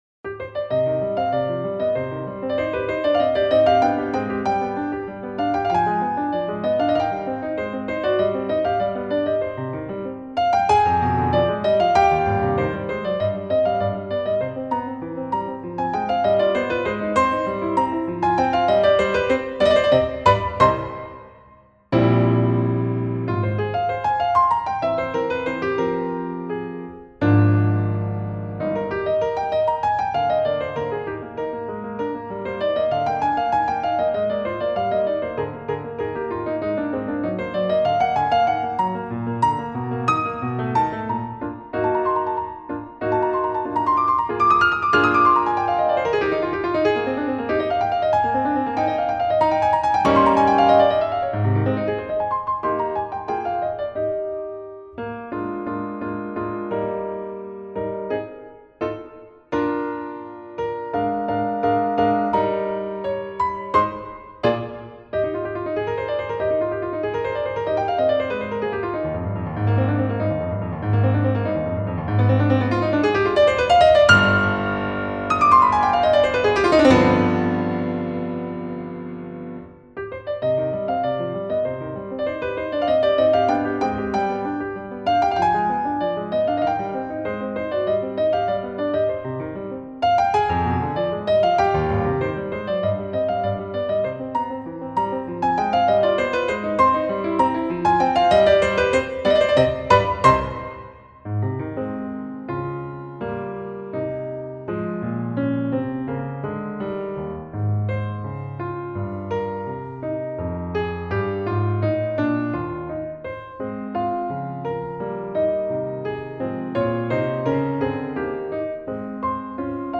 ピアノソナタ C-moll